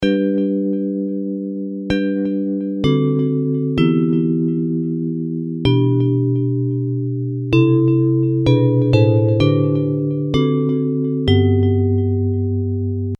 Hymns of praise